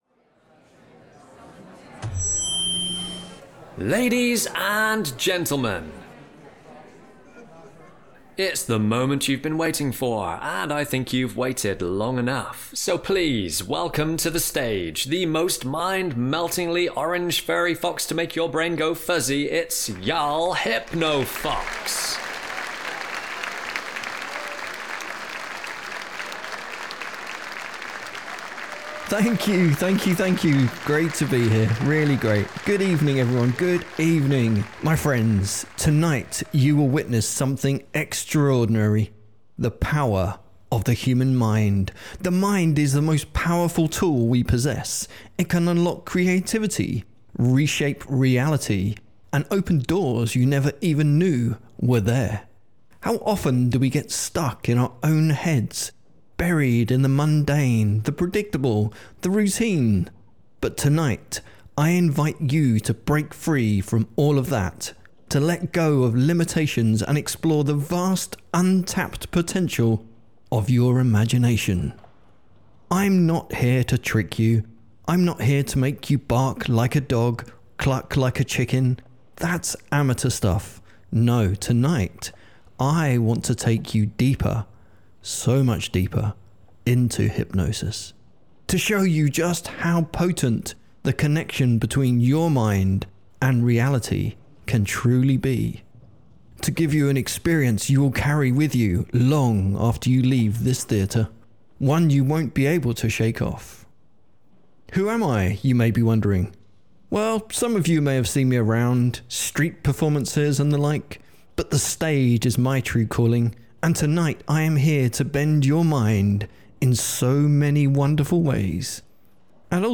Just a little hypno stageshow roleplay, where the audience participates unknowingly in the show.